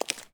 Footstep_Dirt_02.wav